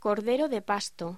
Locución: Cordero de pasto